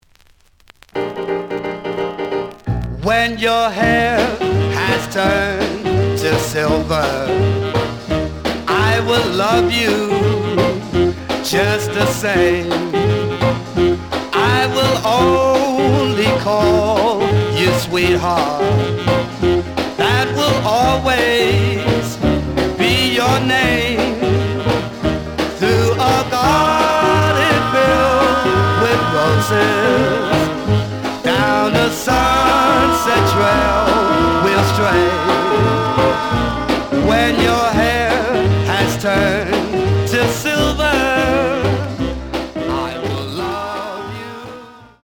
The audio sample is recorded from the actual item.
●Genre: Rhythm And Blues / Rock 'n' Roll
Some click noise on middle of both sides due to scratches.